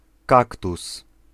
Ääntäminen
Ääntäminen Tuntematon aksentti: IPA: /ˈkakt̪us̪/ Haettu sana löytyi näillä lähdekielillä: puola Käännös 1. cacto {m} 2. cactus {m} Suku: m .